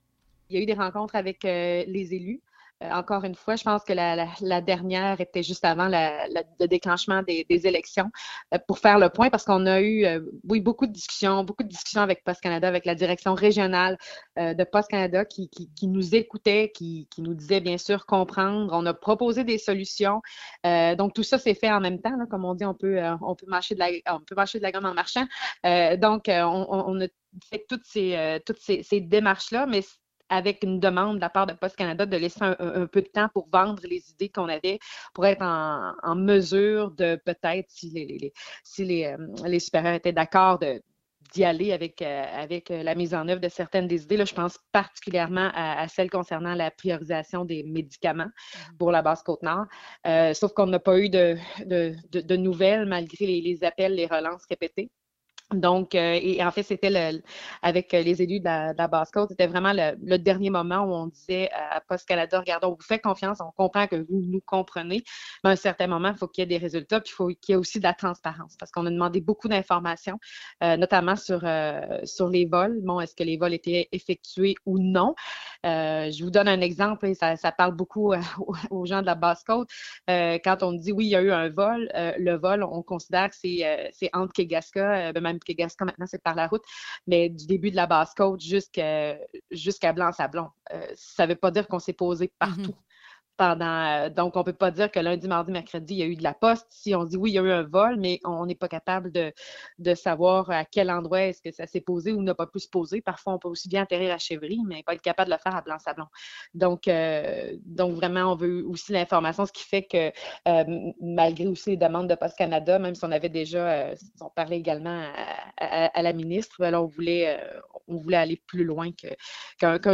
Postes-Canada-segment-radio.mp3